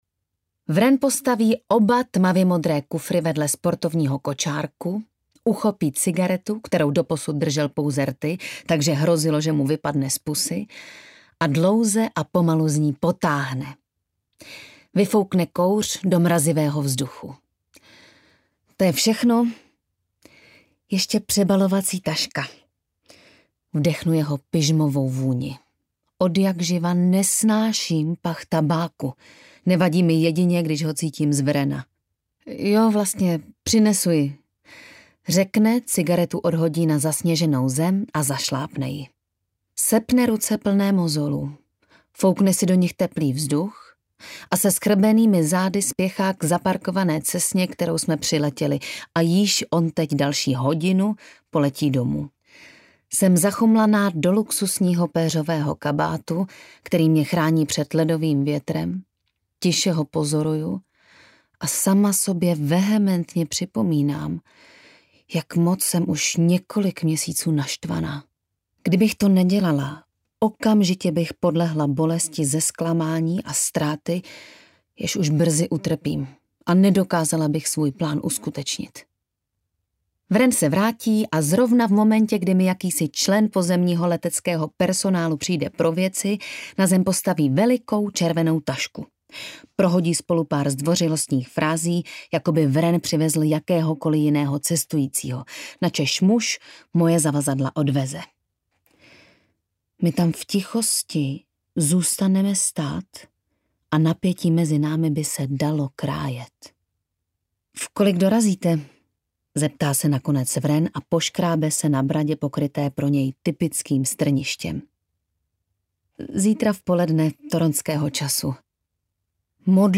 Nespoutaná Aljaška audiokniha
Ukázka z knihy
nespoutana-aljaska-audiokniha